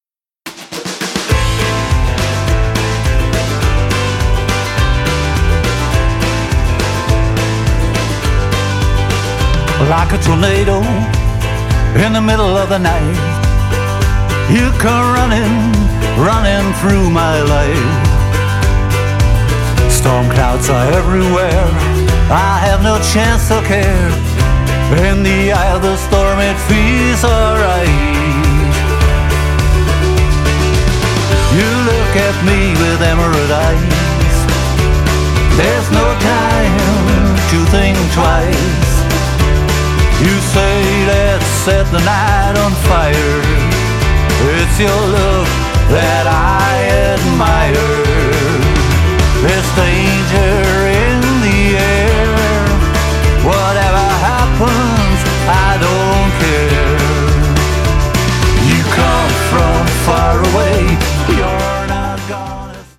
My journey towards Americana continues…
Vocals and harp
Drums, keyboards, string arrangement